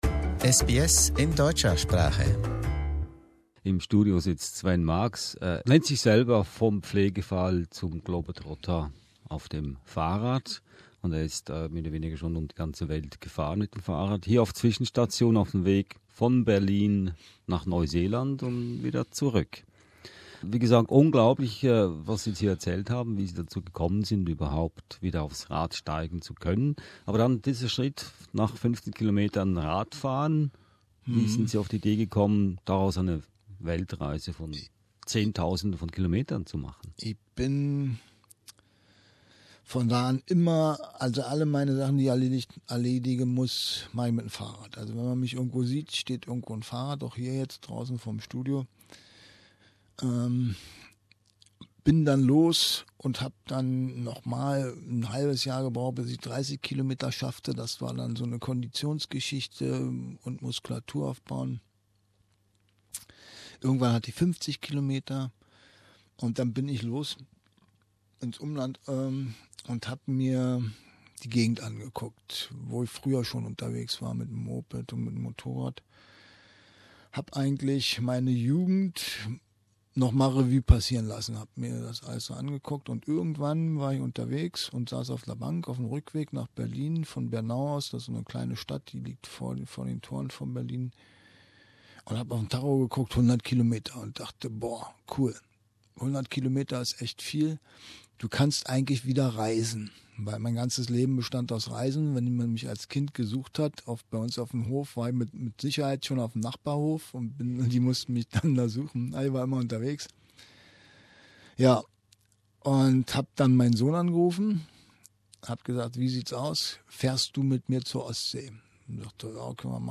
Er machte einen Zwischenhalt in den SBS Studios in Melbourne, um seine faszinierende Geschichte zu erzählen.